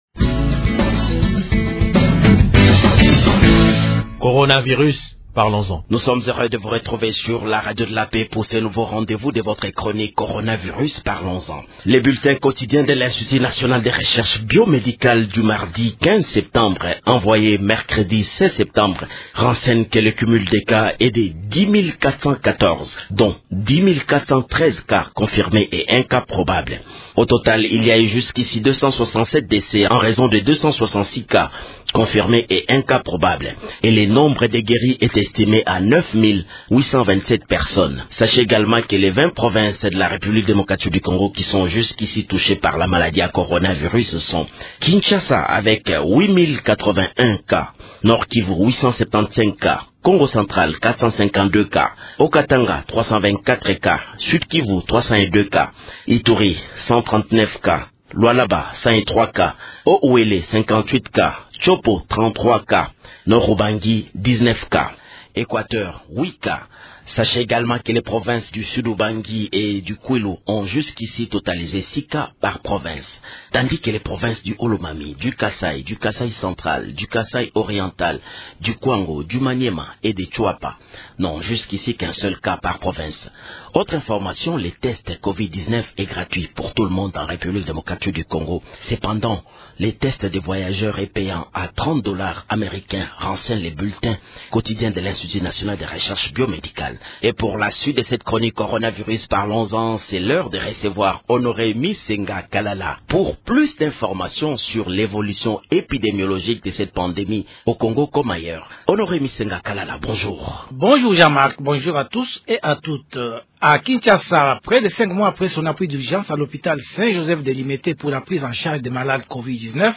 Actualité
Question